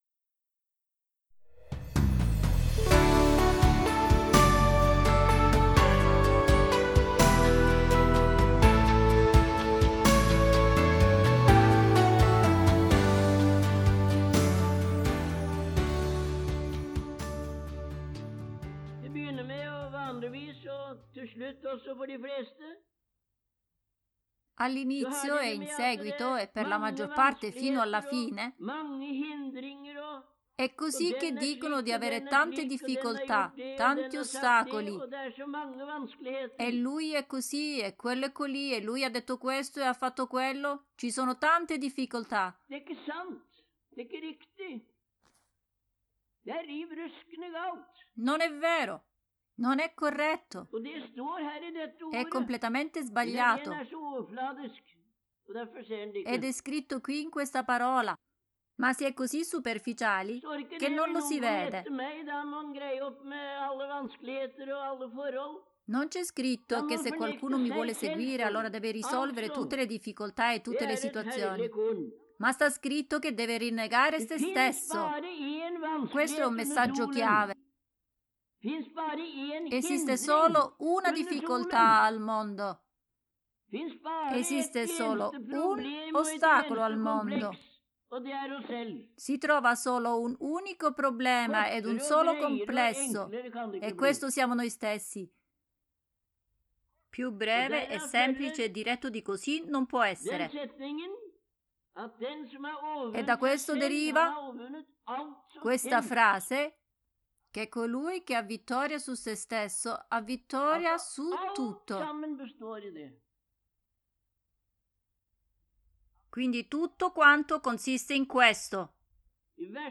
Estratto di un messaggio di gennaio 1973